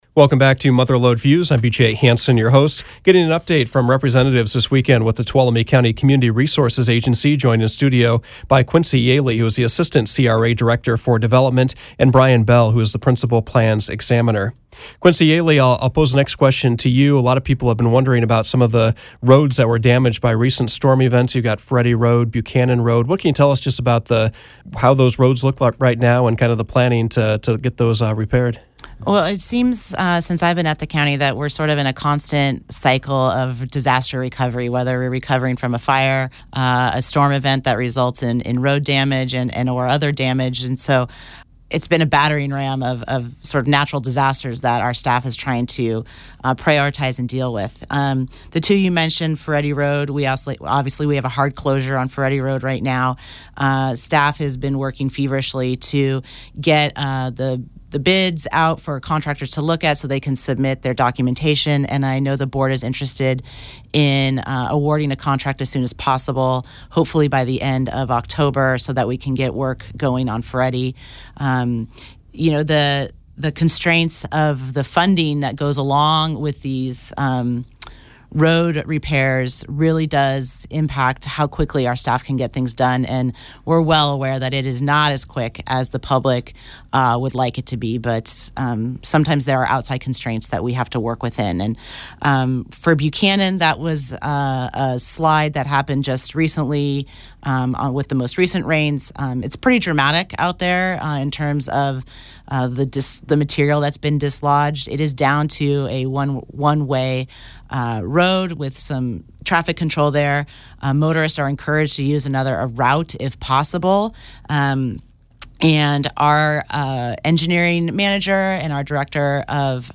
Mother Lode Views featured a pair of guests from the Tuolumne County Community Resources Agency. Topics included development projects in the works, the new General Plan update, storm damaged road projects, and post fire recovery.